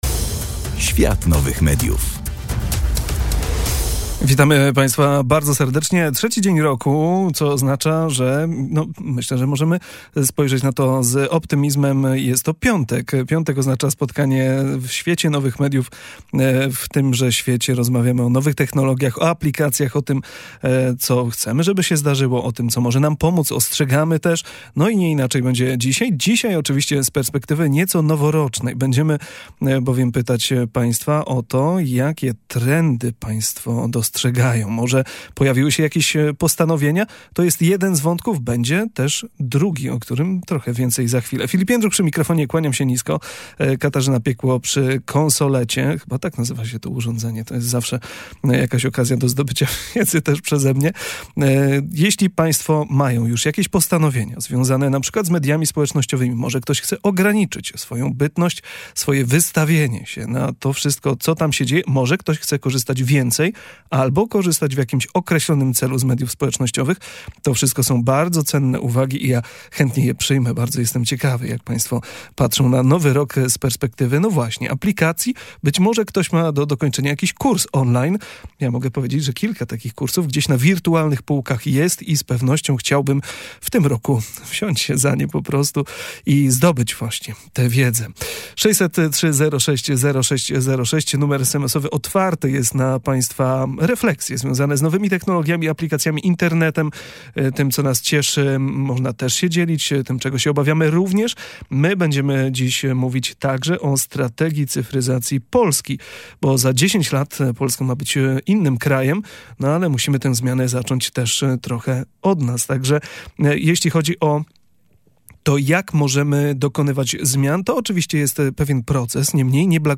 W pierwszej audycji w 2025 roku rozmawialiśmy o trendach, które już teraz kształtują nasze codzienne życie w Internecie i Strategii